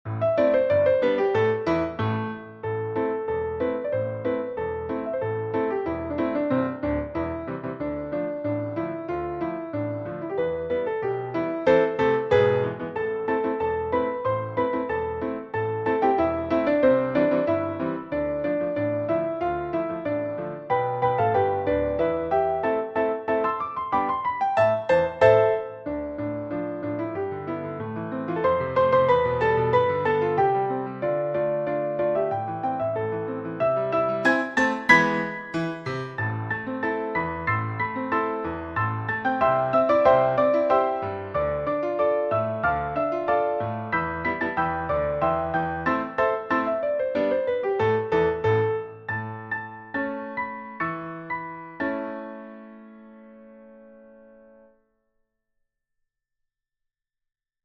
Key: C major, A minor (C, Am)